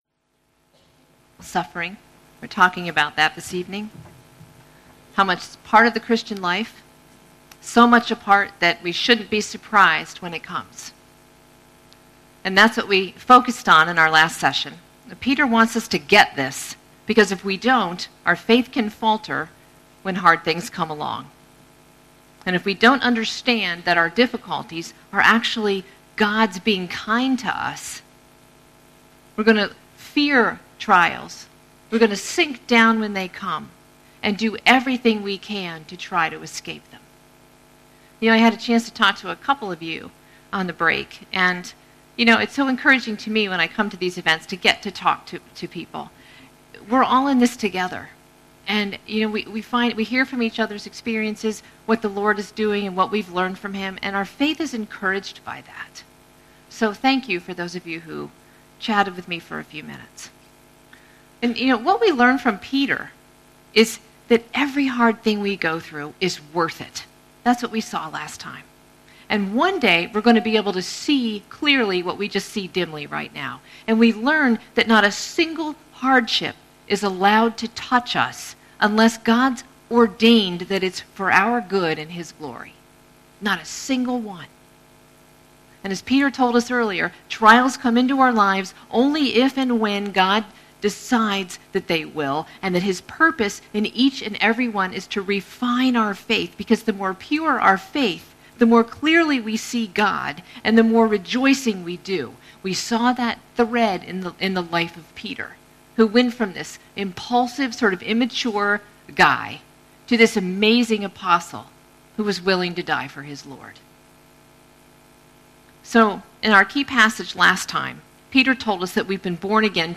catch up on the talks from our BW Spring Conference 2020